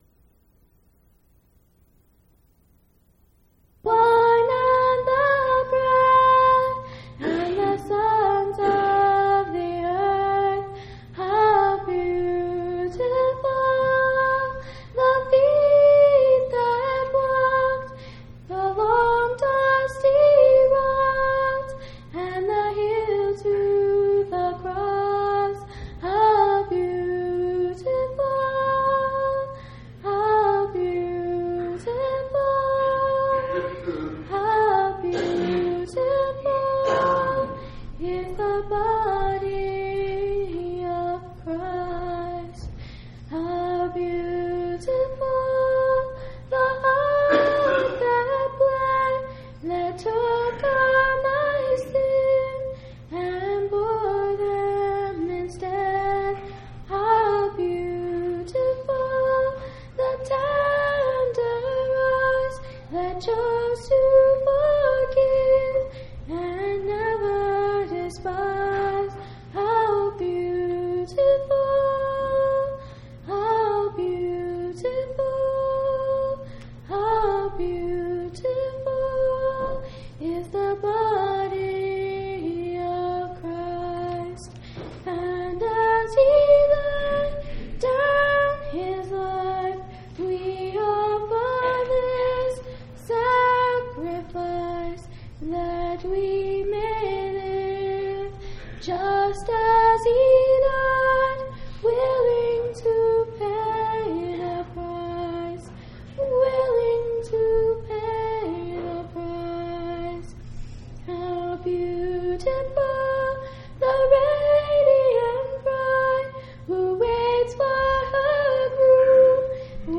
4/7/1999 Location: Temple Lot Local (Conference) Event: General Church Conference